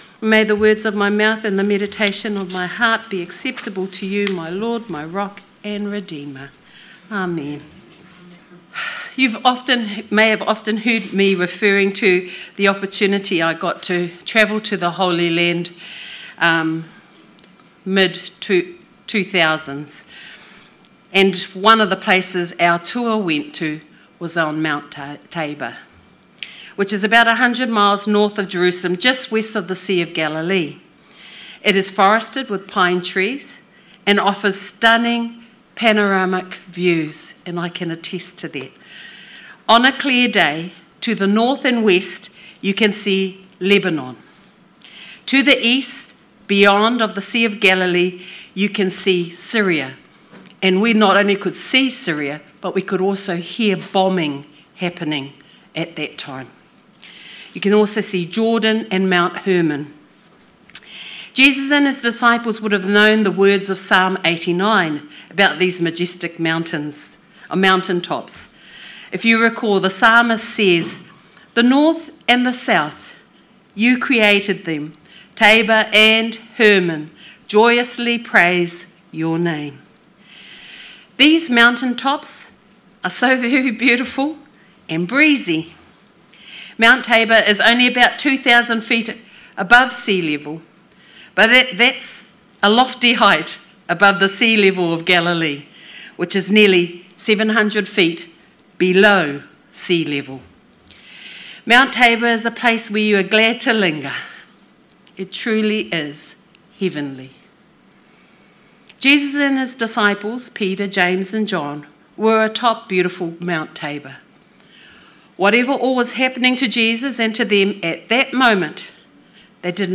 Sermon 15th February 2026